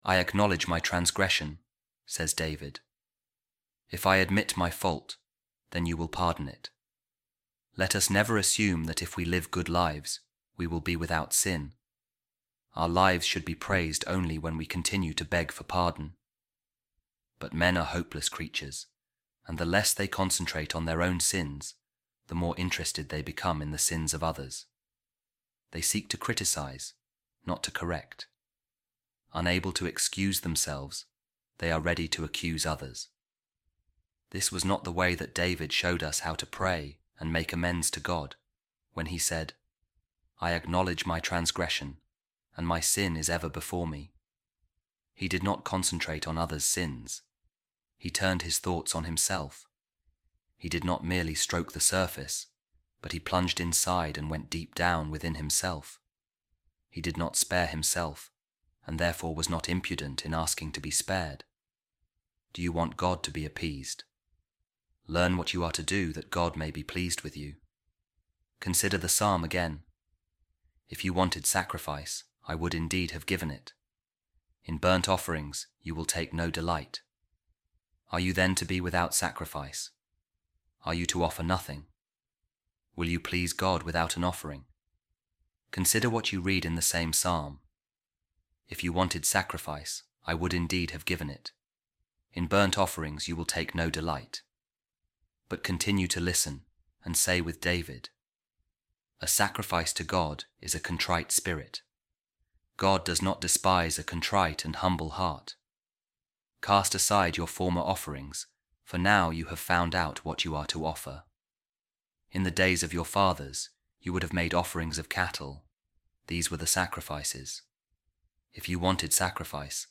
A Reading From The Sermons Of Saint Augustine | A Contrite Heart Is A Sacrifice To God